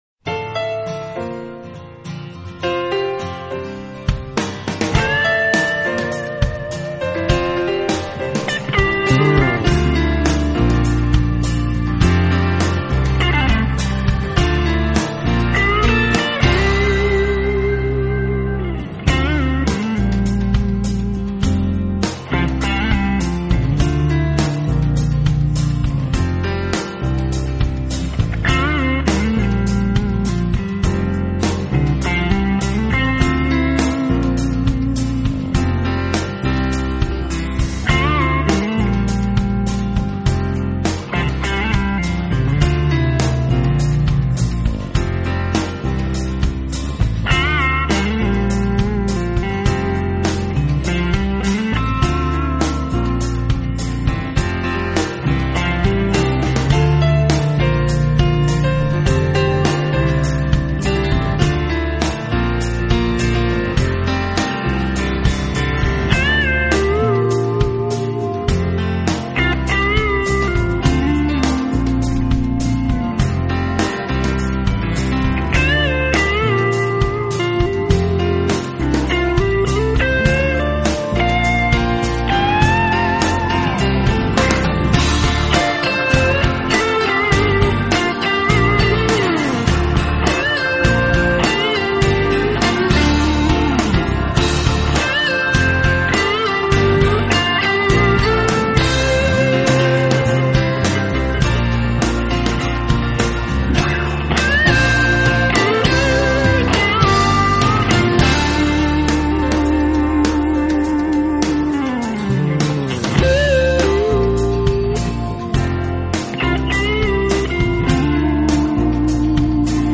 【爵士吉他】
音乐类型：Jazz